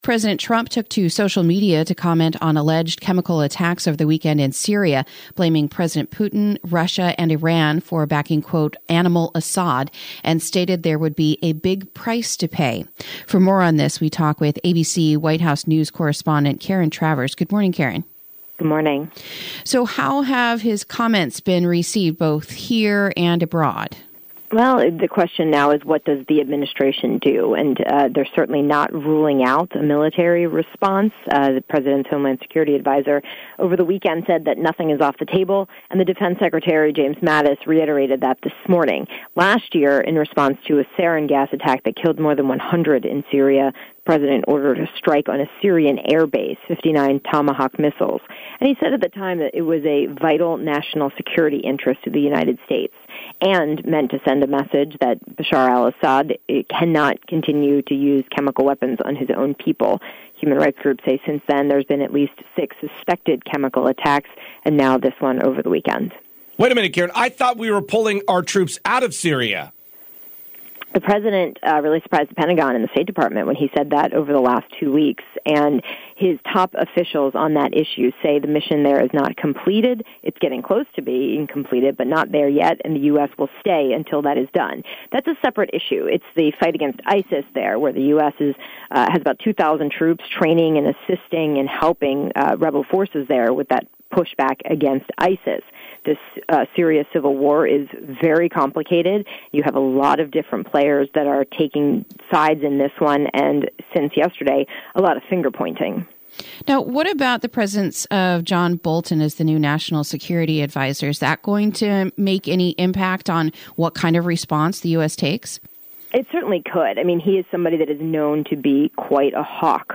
Interview: The POTUS Slams President Putin, Russia and Iran in Regards to the Syria Attacks